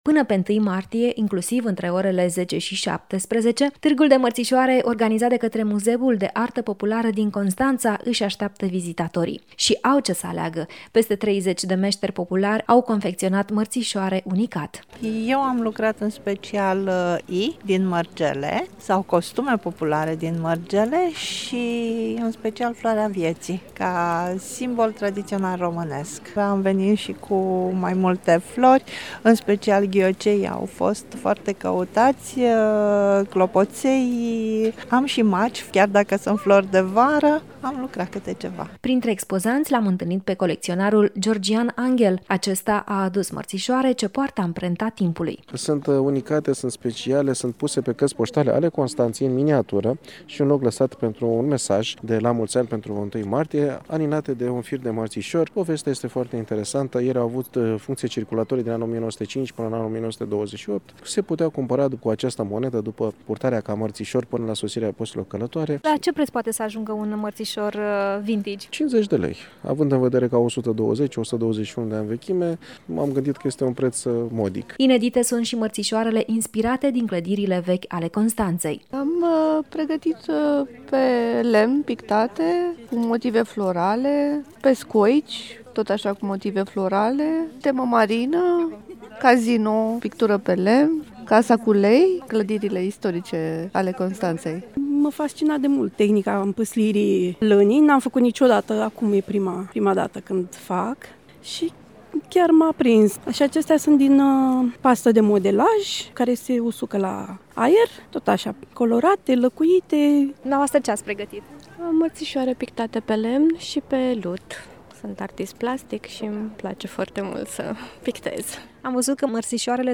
AUDIO: Târg de Mărțișoare deschis pe platoul din fața Muzeului de Artă Populară Constanța
a vorbit cu expozanții, dar și cu vizitatorii târgului.